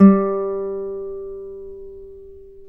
Index of /90_sSampleCDs/Roland L-CD701/GTR_Nylon String/GTR_Classical